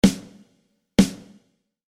Drum-Tuning
Satter, bassbetonter Sound mit warmen Obertönen
Prädestiniert für diesen Sound sind Snaredrums in den Dimensionen 14"-x-6,5" (mit Messing- oder Holzkessel, vorzugsweise Birke) sowie 14"-x-7"- oder 14"-x-8"-Holzkessel-Snaredrums.
Die Snaredrum wird nun relativ stark gedämpft.